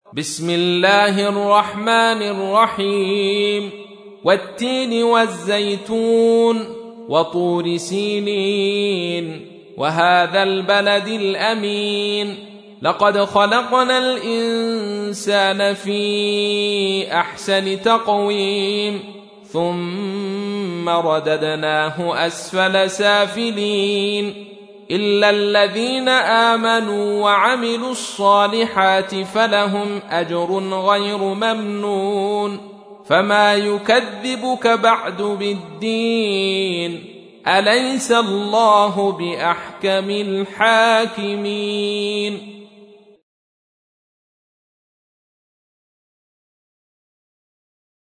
تحميل : 95. سورة التين / القارئ عبد الرشيد صوفي / القرآن الكريم / موقع يا حسين